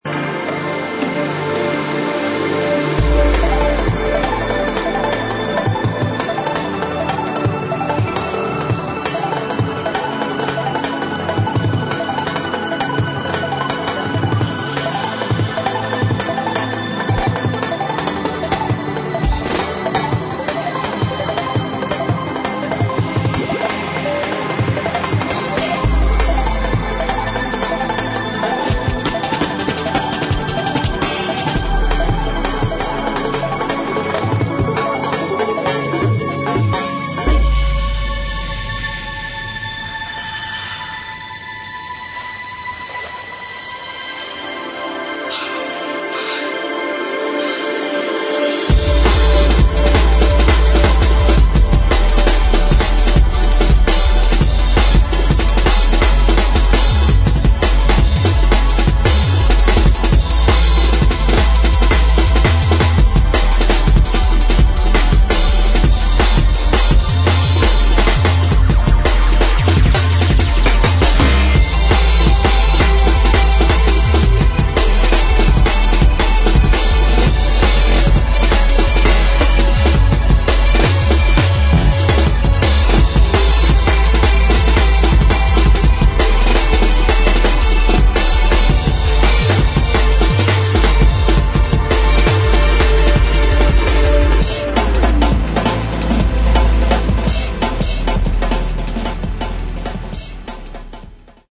DRUM'N'BASS | JUNGLE